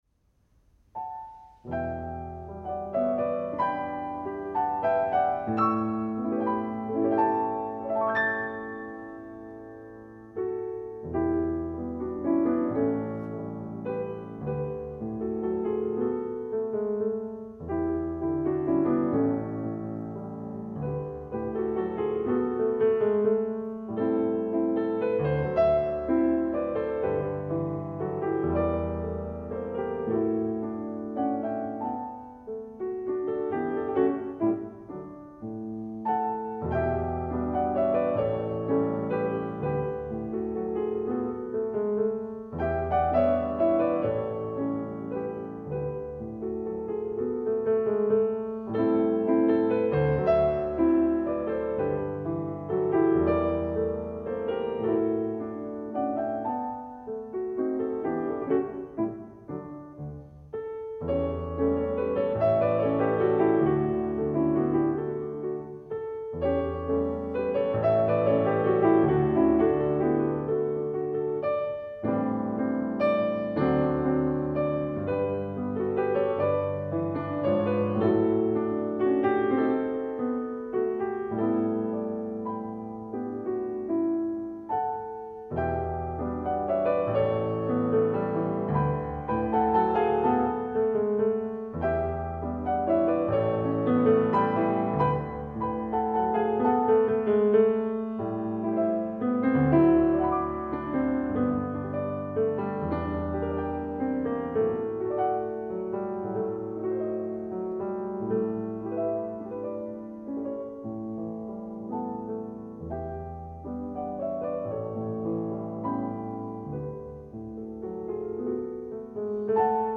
Genre :  ChansonComptine
Style :  Avec accompagnement
Enregistrement piano seul